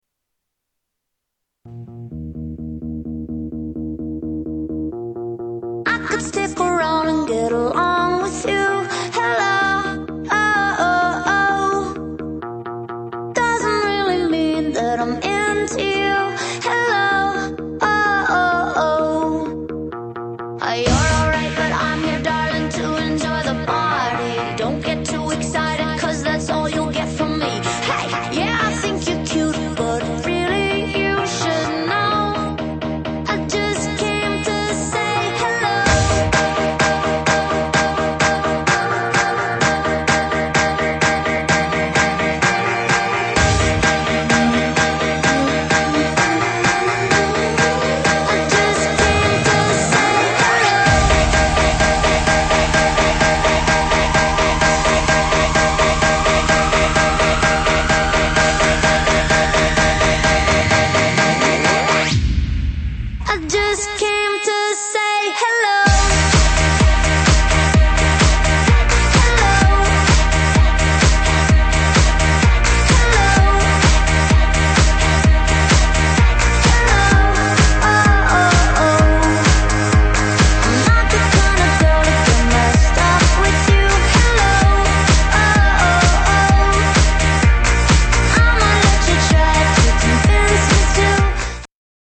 Господа опознайте что за мелодия играет на заднем плане ? Фрагмент из матча.